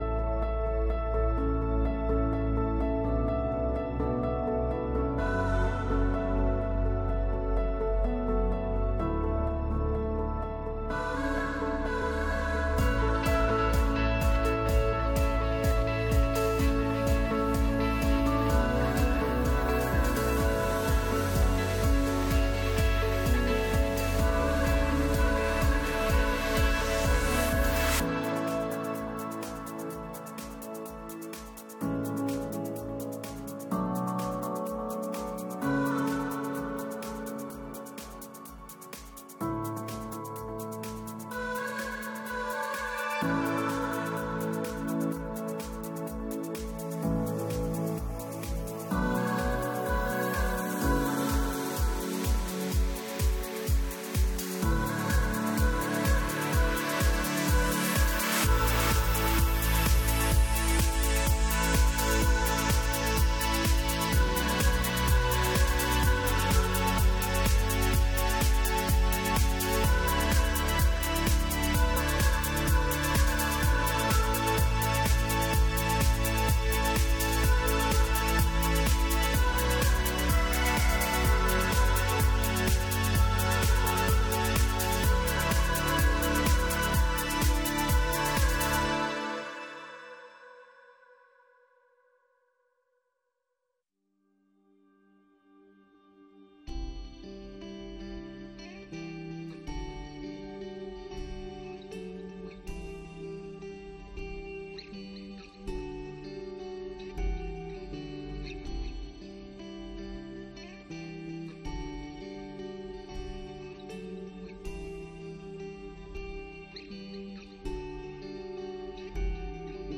This Sunday, CPC joined WRC for a combined service.